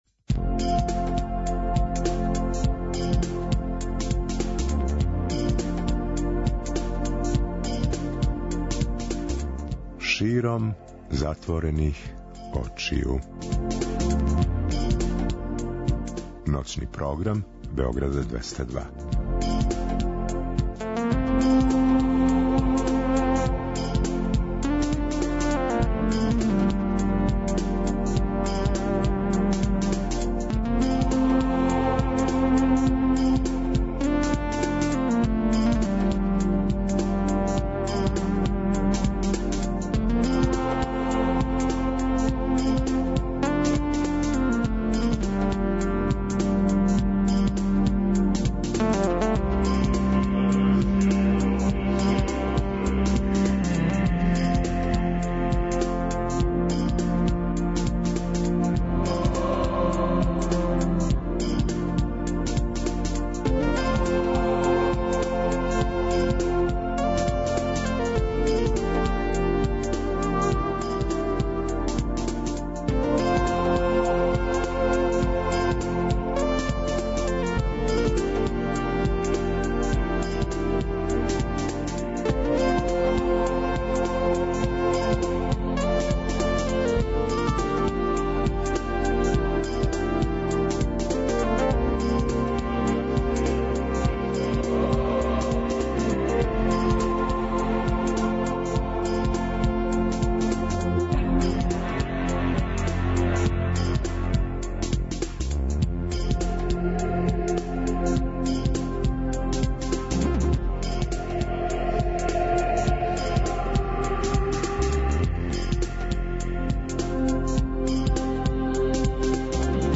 Ноћни програм Београда 202